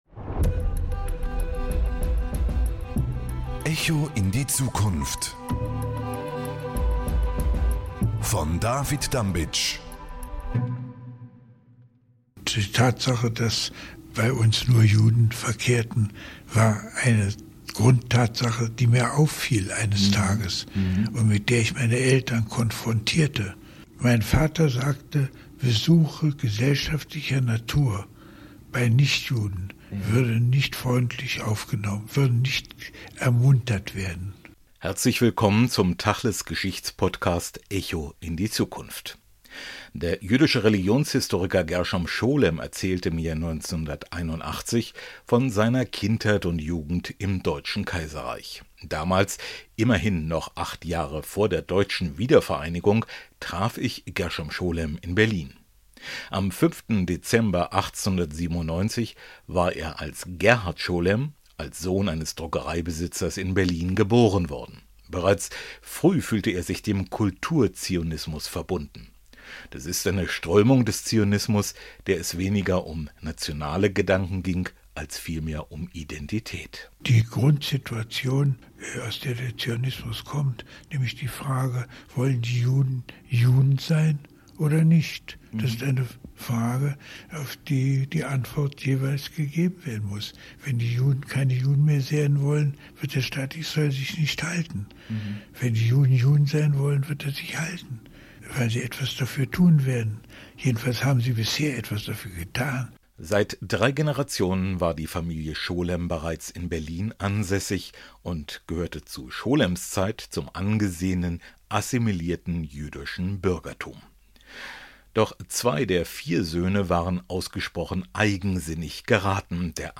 Der neue tachles-Podcast «Echo in die Zukunft» blickt zurück und damit vorwärts zugleich: Er vereint Interviews von Persönlichkeiten der Zeitgeschichte. In dieser ersten Episode spricht der Religionshistoriker Gershom Scholem über Judentum, Zionismus, Israel und zeigt auf, unter welches die Konditio für ein Untergang Israels wäre.